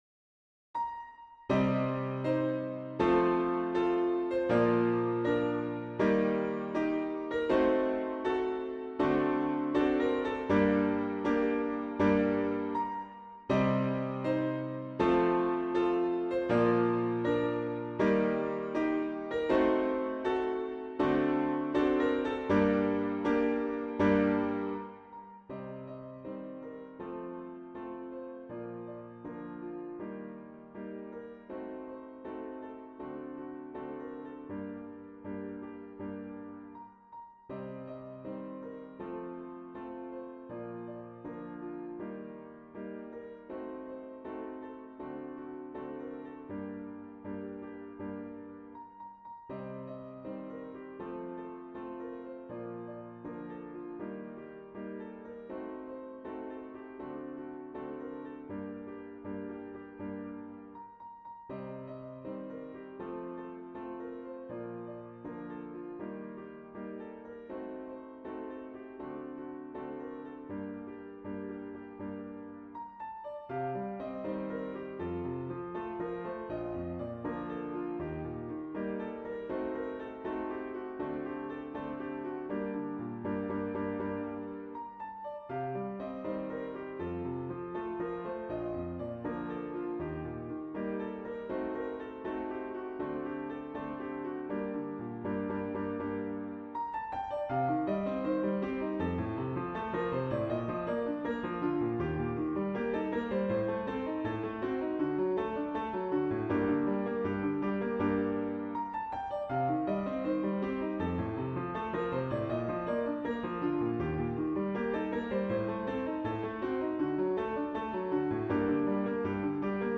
TEN ORIGINAL COMPOSITIONS FOR PIANO